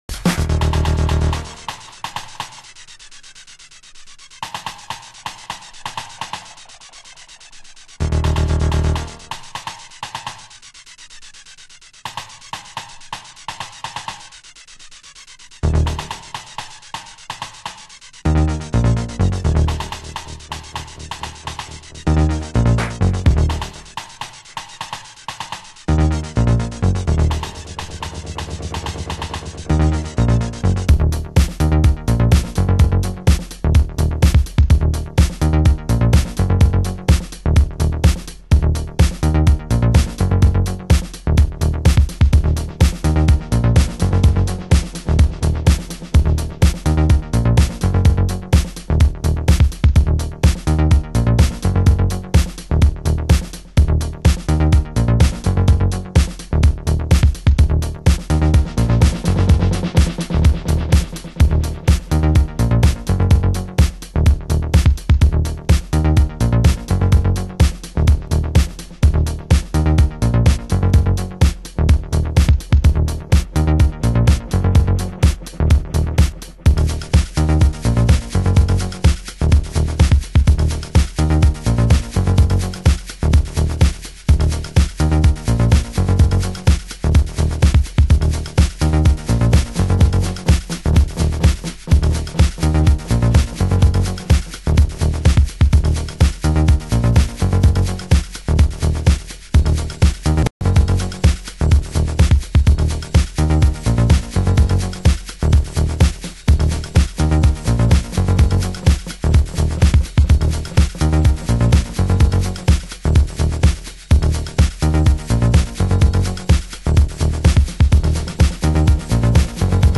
DISCODUB
FUNK